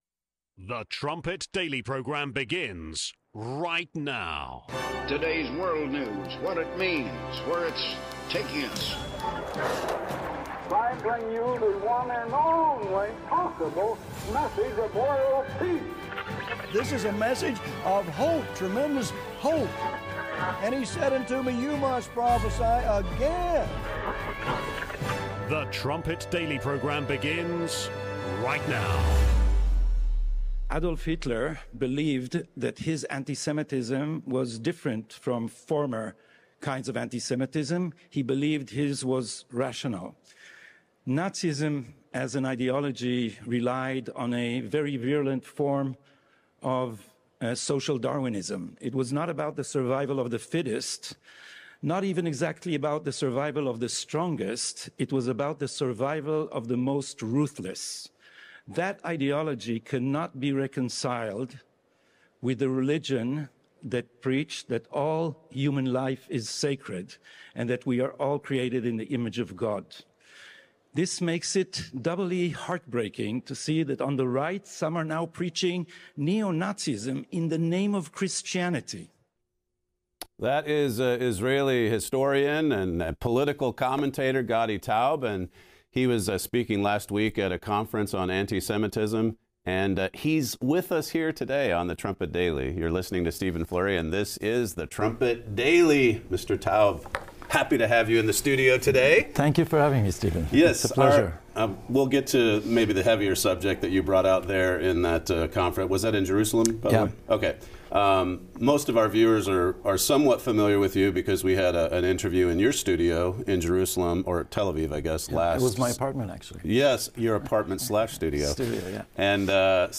00:30 Trumpet Daily Interview